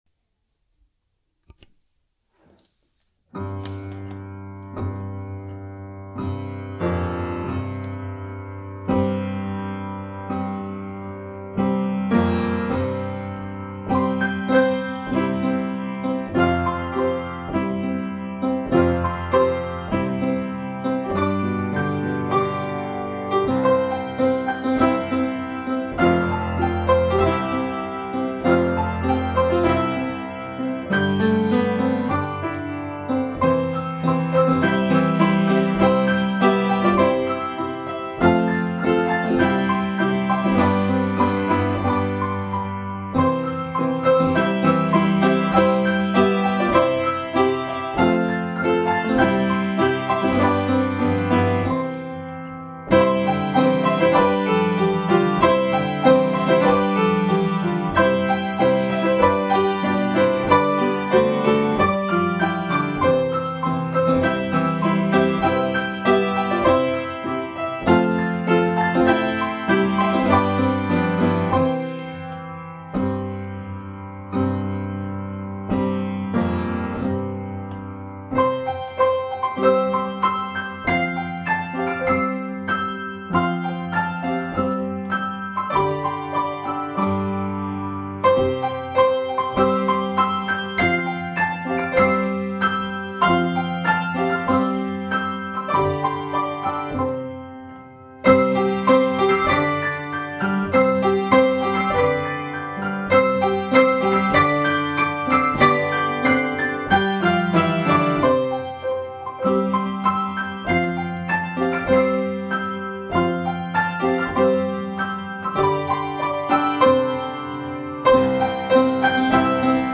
Voicing/Instrumentation: Piano Duet/Piano Ensemble We also have other 34 arrangements of " Praise to the Man ".